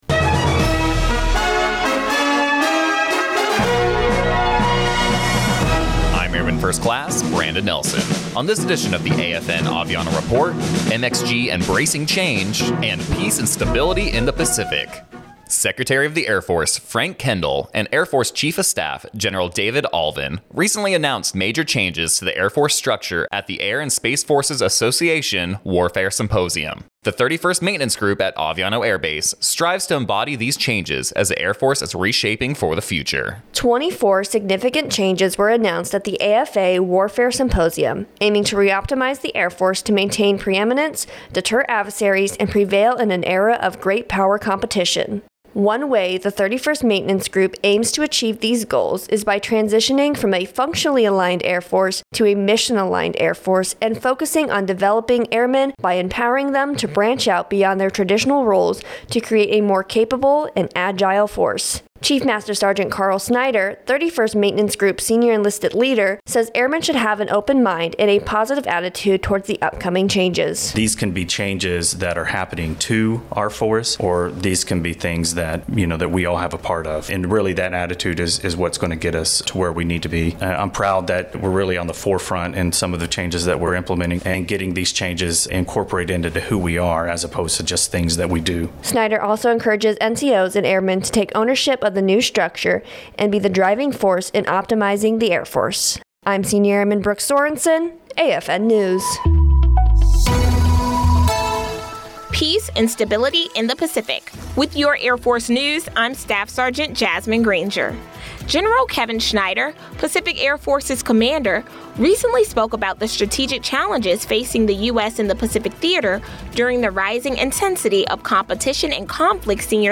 American Forces Network (AFN) Aviano radio news reports on changes announced at the Air and Space Forces Association Warfare Symposium and the steps the 31st Maintenance Group is taking to keep up with the reshaping of the Air Force.